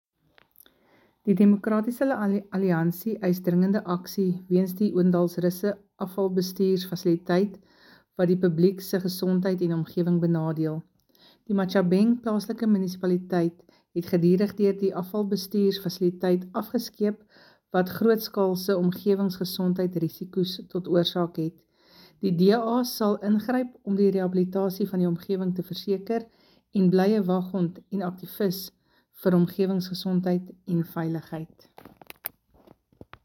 Afrikaans soundbites by Cllr Jessica Nel and Sesotho soundbite by Jafta Mokoena MPL.